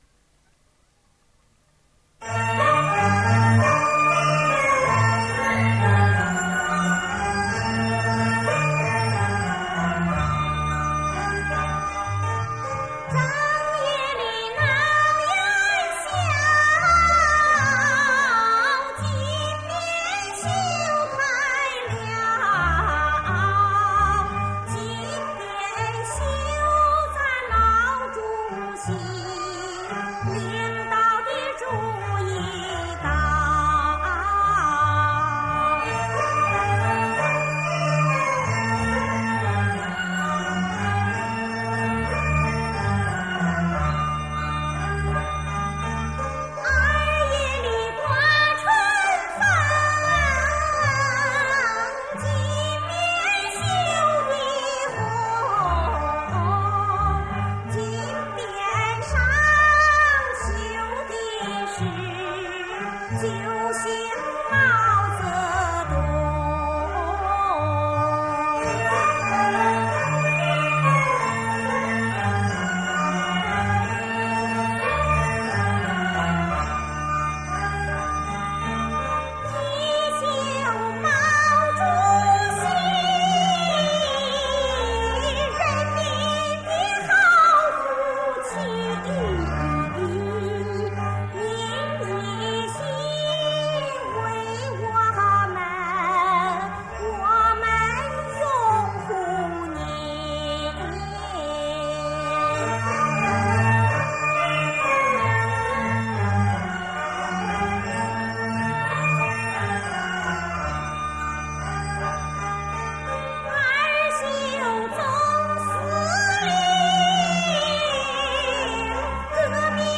磁带数字化
单声道